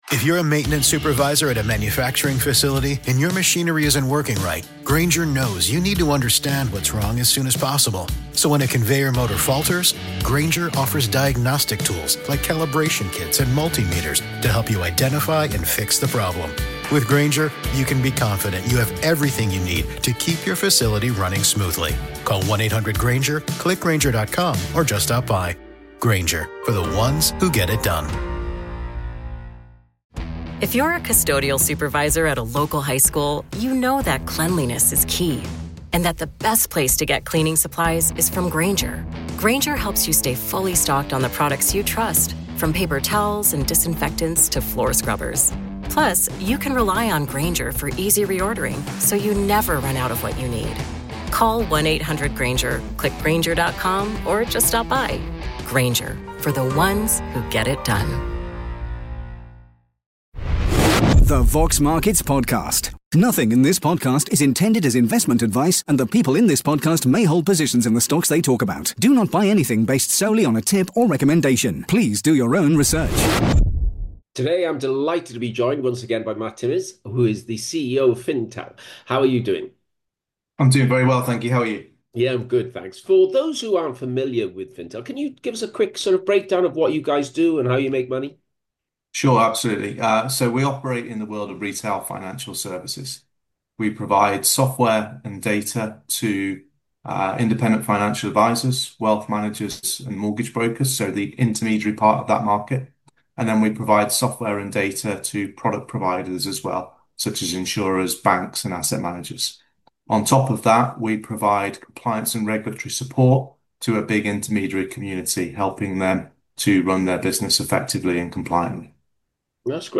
Interview with CEO